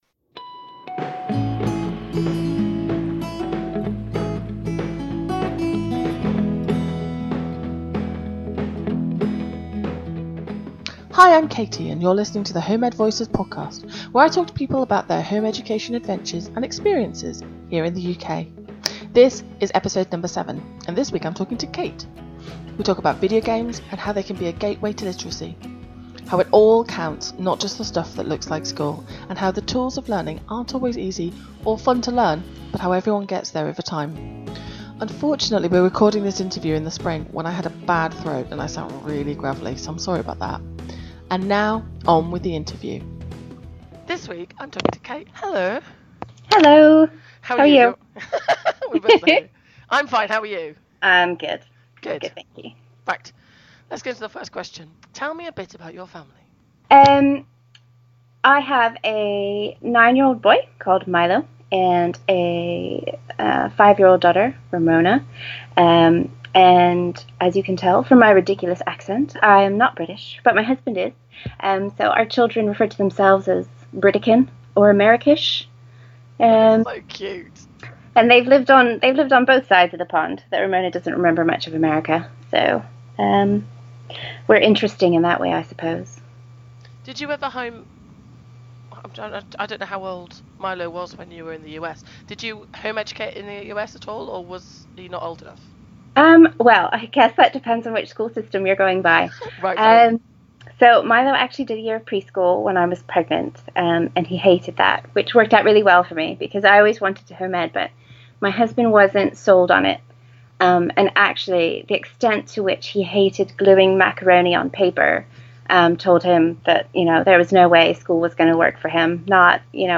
We talk about videogames and how they can be a gateway to literacy. How it all counts, not just the stuff that looks the most like school, and how you can structure your yearly plan to protect your mental health. Unfortunately, when we recorded this interview in the spring I had a bad throat and so sound really gravely, so sorry about that.